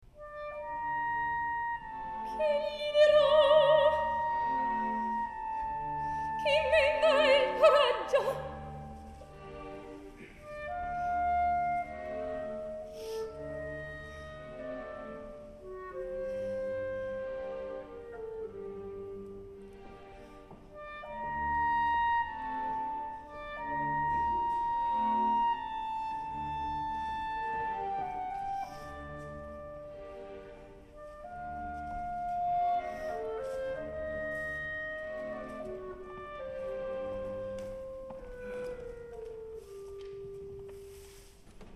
All of the audio clips below are in mp3 format and excerpted from live recordings.
Orchestral
Peabody Concert Orchestra